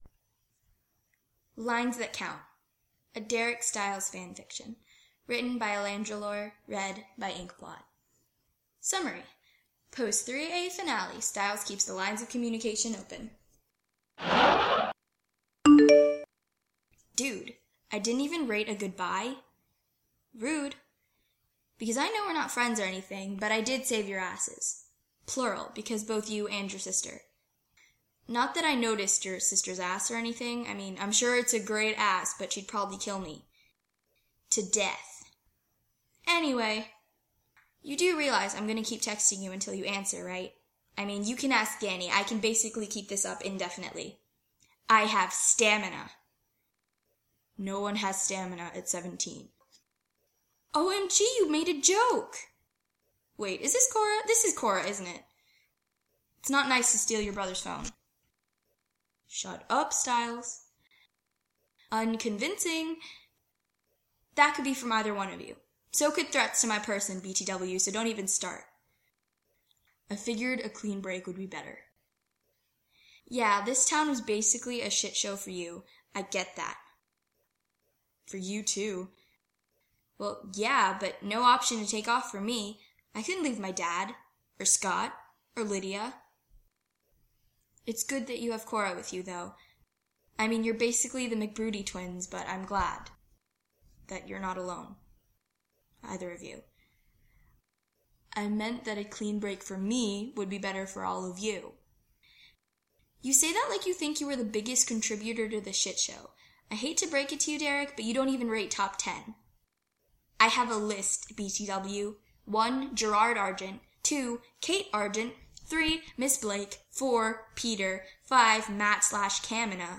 [podfic]